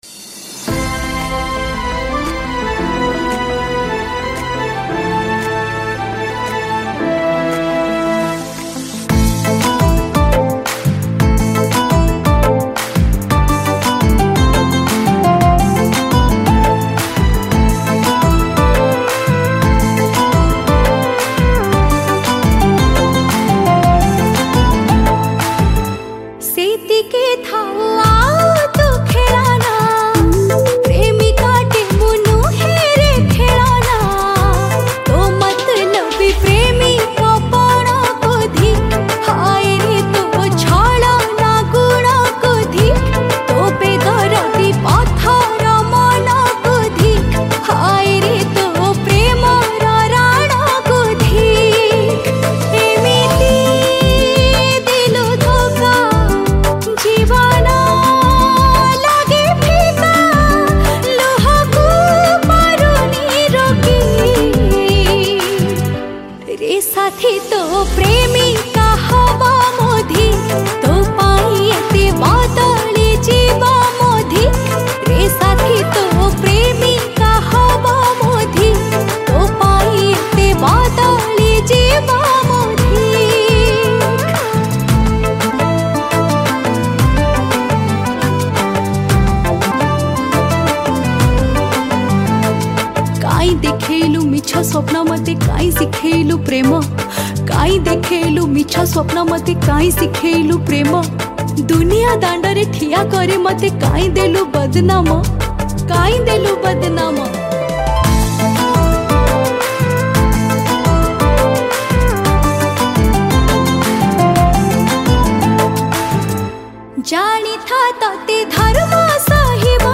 Female Version